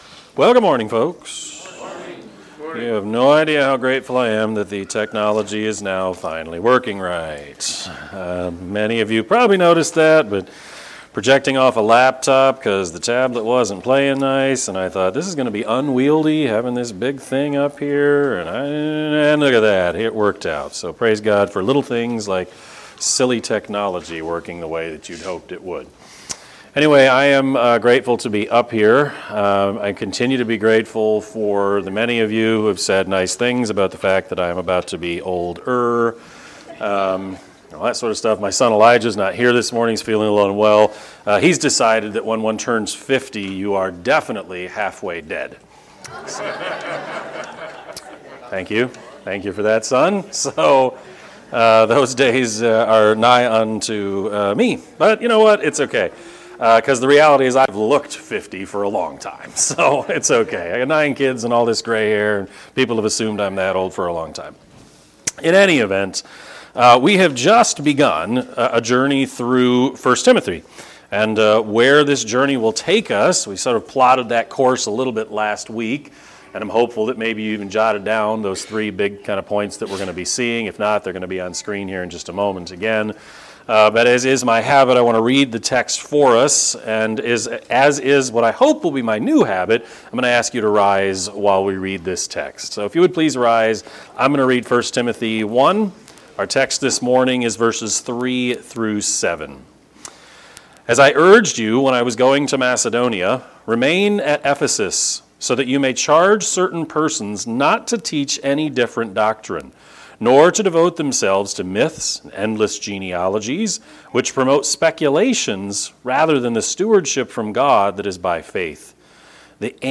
Sermon-4-23-23-Edit.mp3